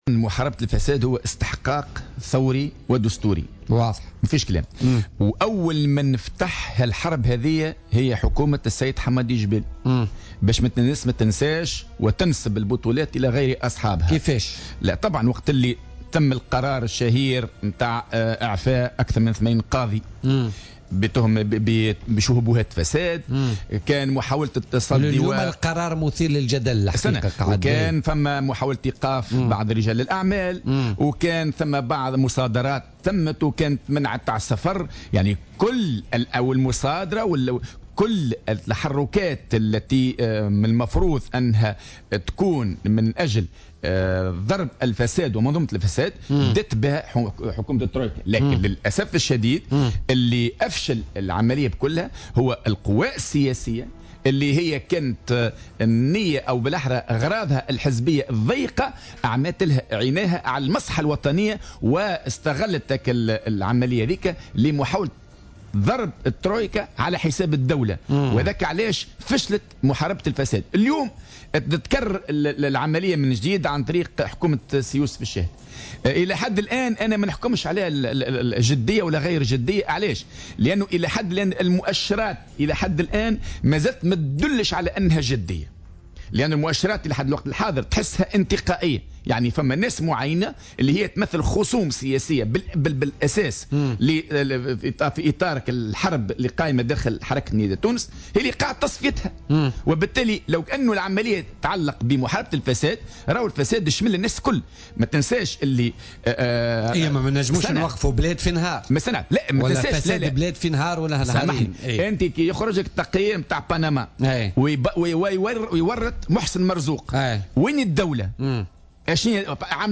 Invité de l'émission Politica de ce mercredi 31 mai 2017